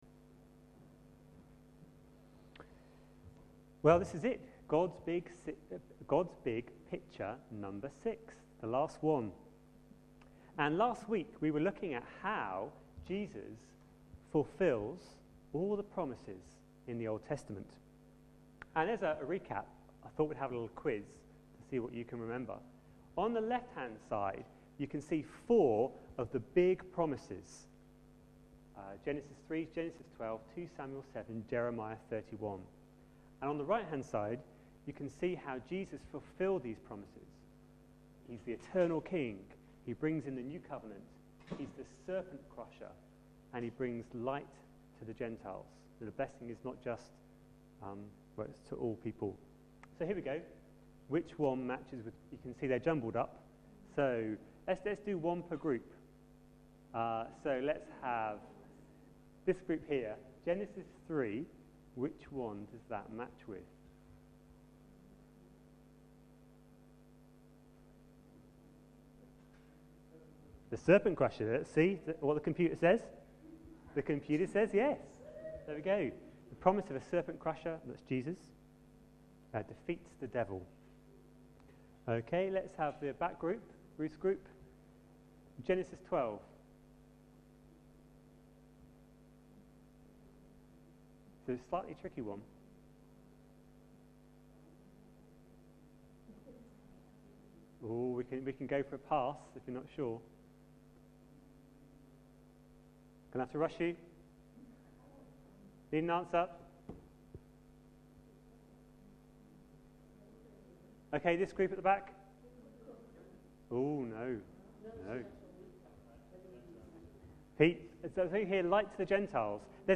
A sermon preached on 21st November, 2010, as part of our God's Big Picture series.